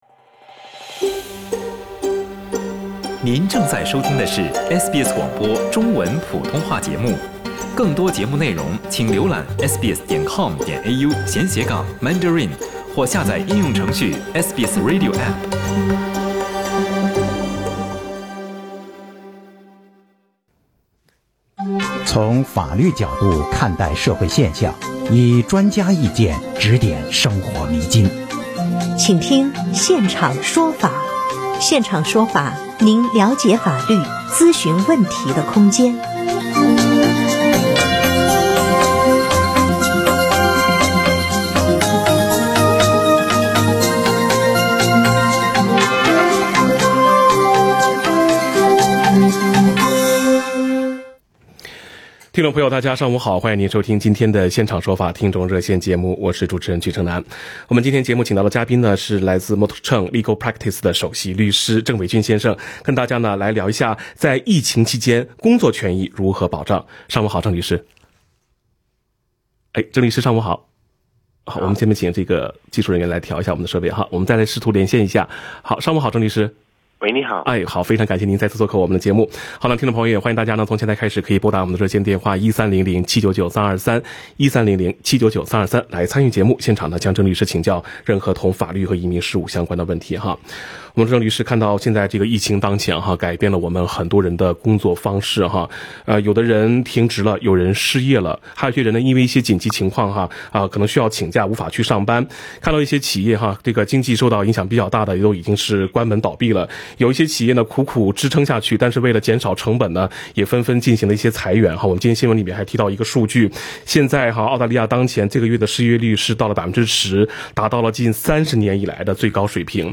本期《现场说法》听众热线